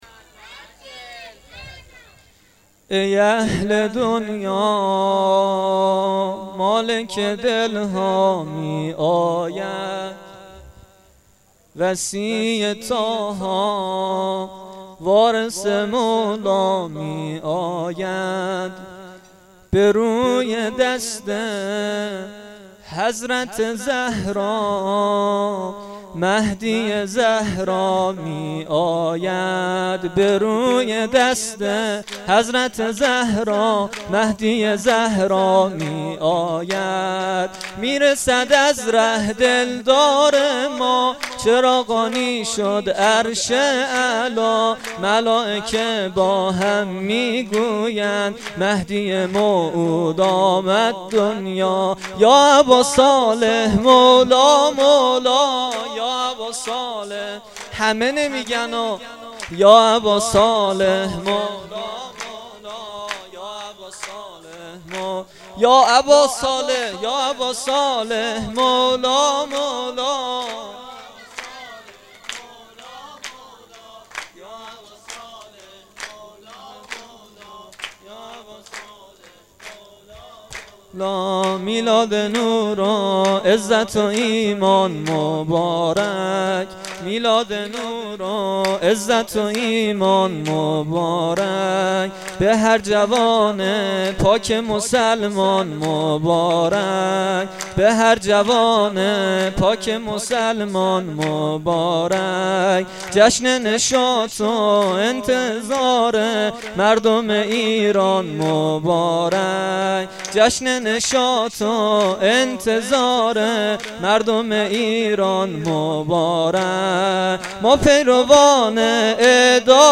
سرود امام زمان